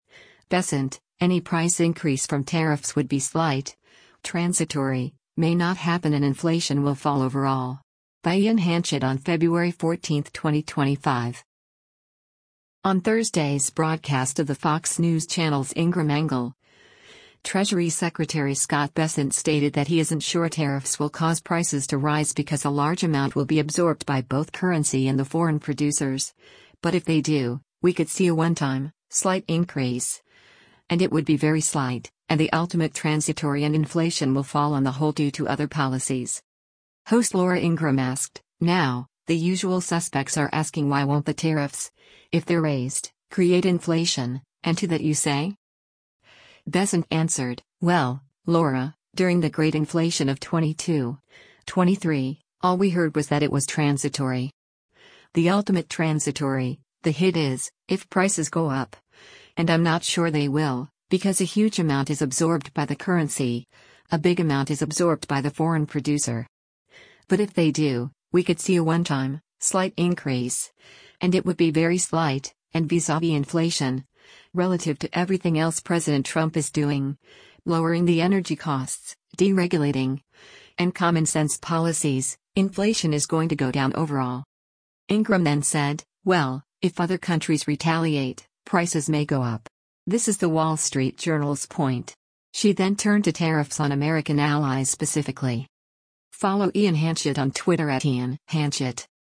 On Thursday’s broadcast of the Fox News Channel’s “Ingraham Angle,” Treasury Secretary Scott Bessent stated that he isn’t sure tariffs will cause prices to rise because a large amount will be absorbed by both currency and the foreign producers, “But if they do, we could see a one-time, slight increase, and it would be very slight,” and “The ultimate transitory” and inflation will fall on the whole due to other policies.
Host Laura Ingraham asked, “Now, the usual suspects are asking why won’t the tariffs, if they’re raised, create inflation, and to that you say?”